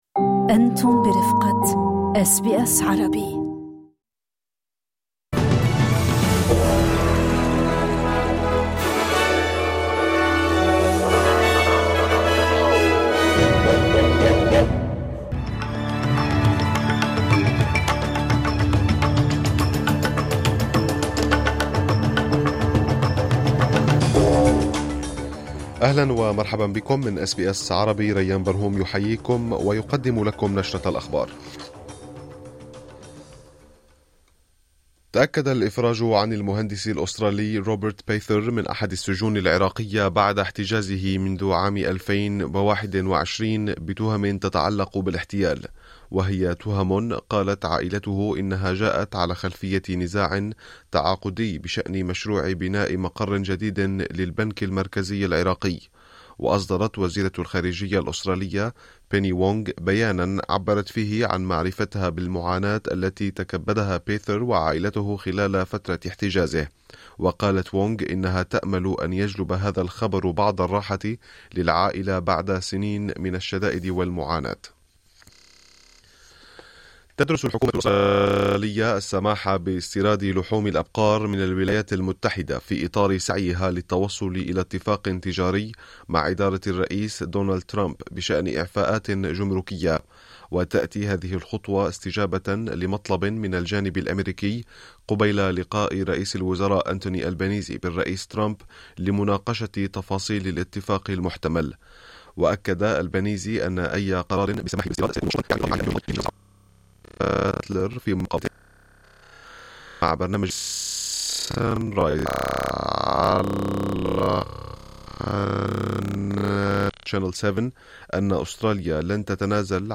نشرة أخبار الظهيرة 06/06/2025